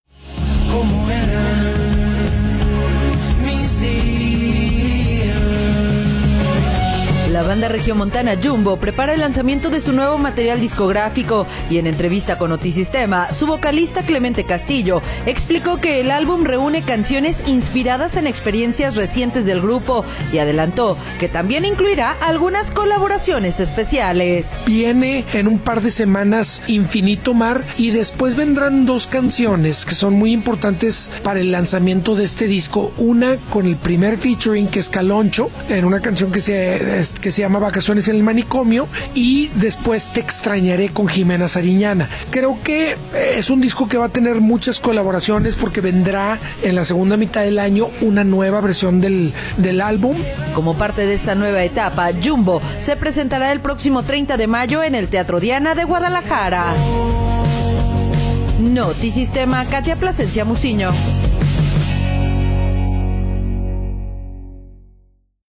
audio La banda regiomontana Jumbo prepara el lanzamiento de su nuevo material discográfico. En entrevista con Notisistema, su vocalista Clemente Castillo explicó que el álbum reúne canciones inspiradas en experiencias recientes del grupo y adelantó que también incluirá algunas colaboraciones especiales.